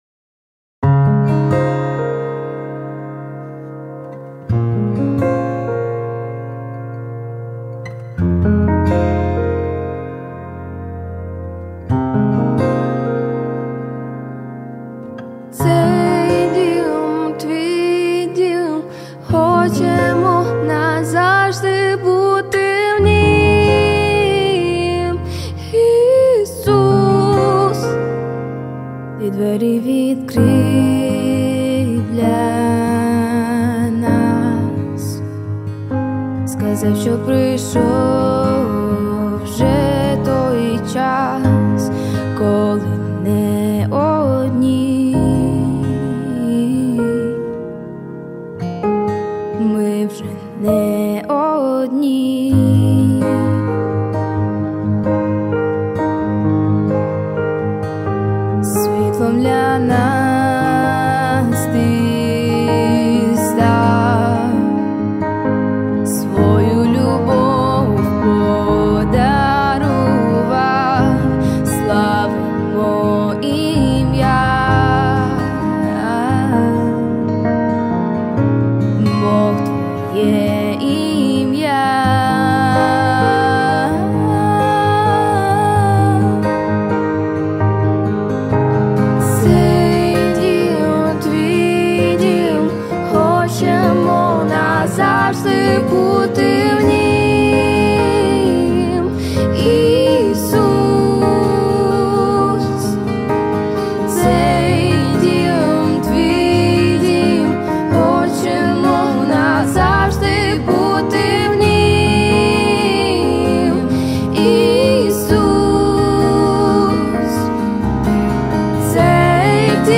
42 просмотра 74 прослушивания 0 скачиваний BPM: 130